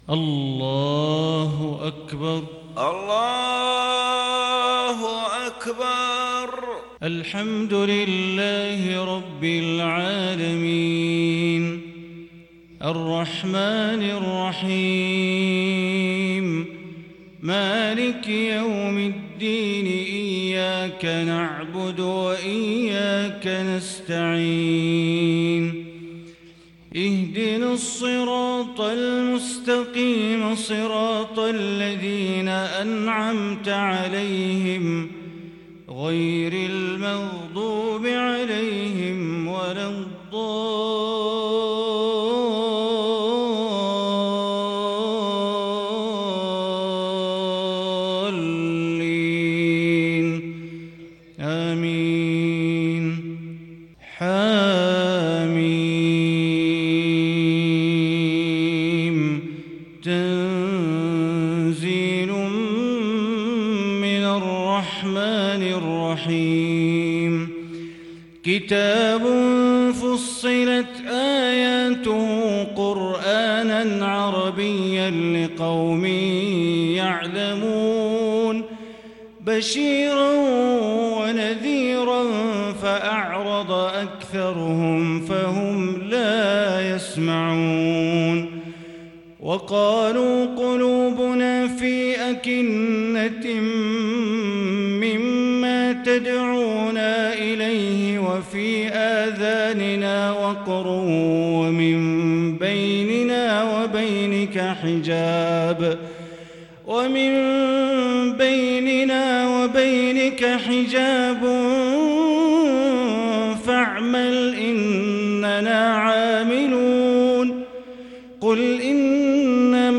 صلاة الفجر للشيخ بندر بليلة 13 صفر 1442 هـ